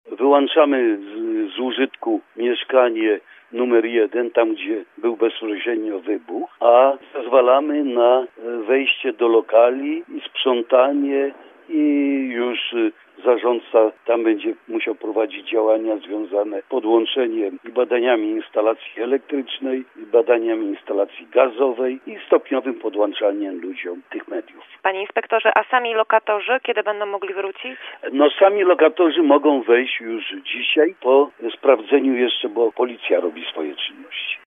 Jesteśmy już po oględzinach – powiedział nam Adam Migdalczyk, powiatowy inspektor nadzoru budowlanego dla miasta Gorzowa.